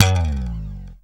Index of /90_sSampleCDs/Roland L-CD701/BS _Jazz Bass/BS _E.Bass FX